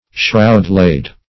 shroud-laid.mp3